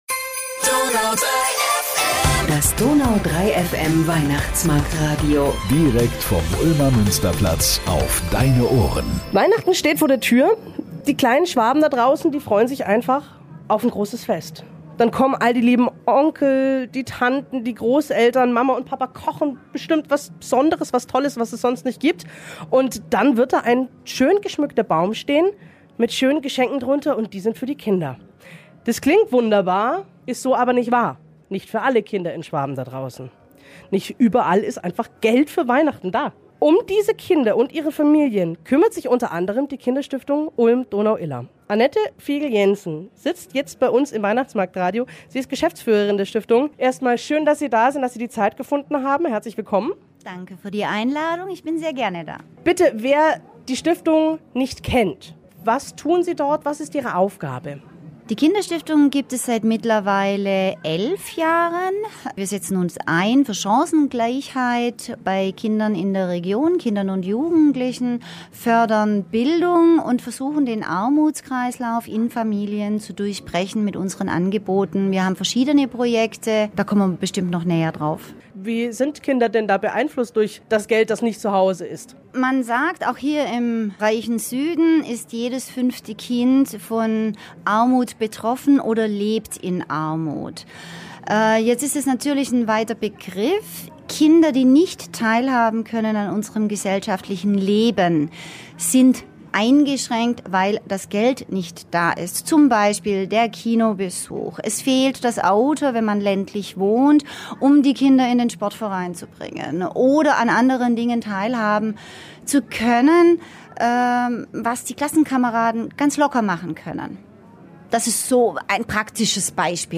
Mit welchen tollen Projekten Kinder stark fürs Leben gemacht werden können und was wir alle in unserem Alltag gegen Kinderarmut tun können, hört ihr im Interview.